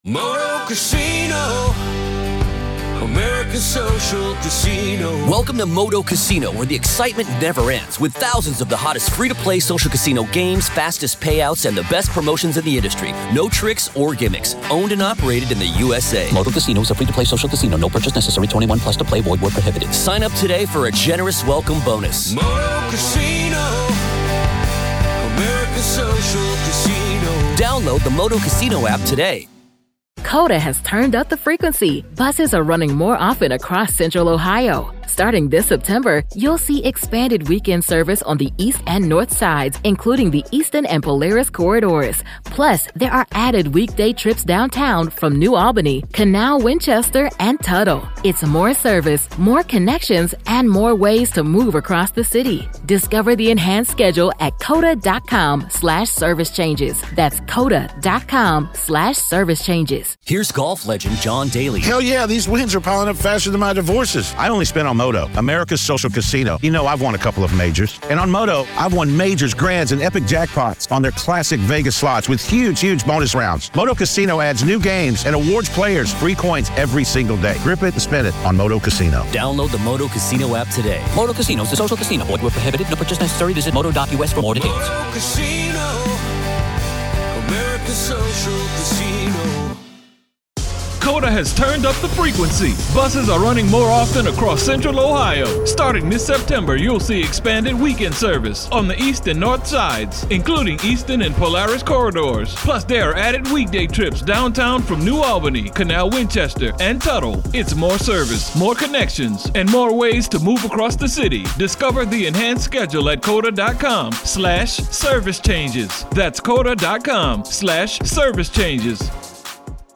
True Crime News & Interviews